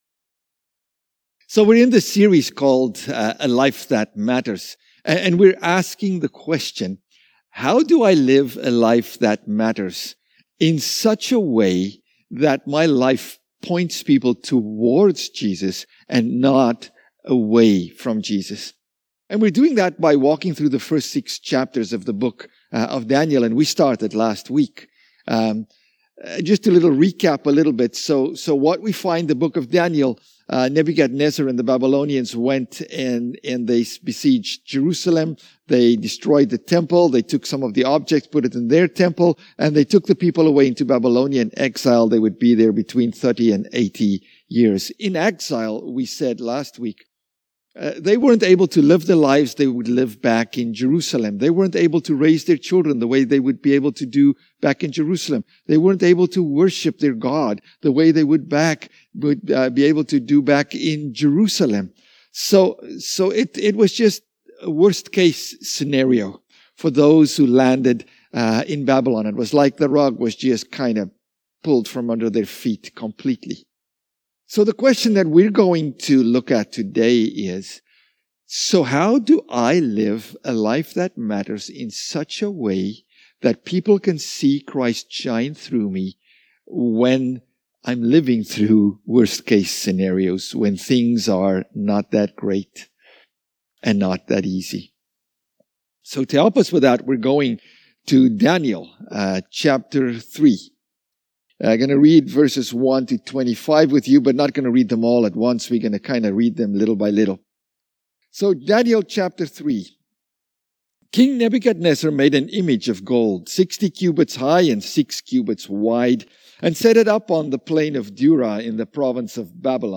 March 6 - Sermon - Central Presbyterian Church Cambridge